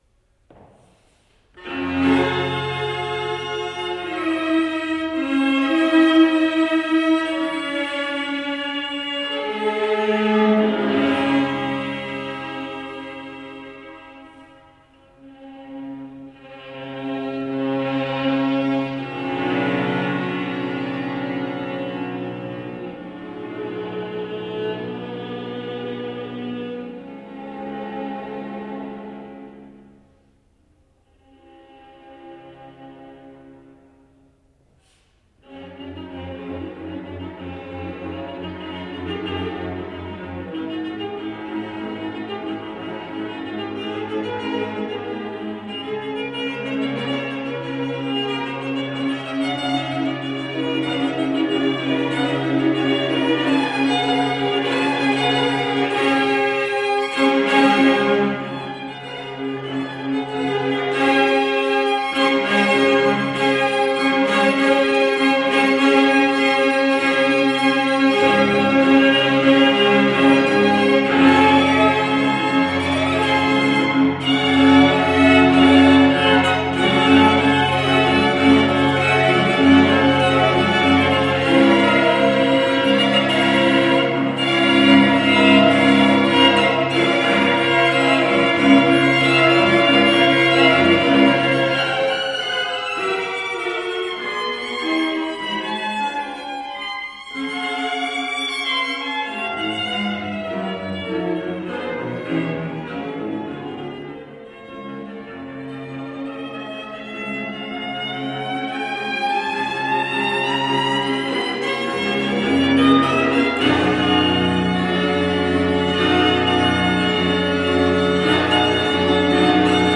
Emerging Artists concert July 18, 2013--afternoon | Green Mountain Chamber Music Festival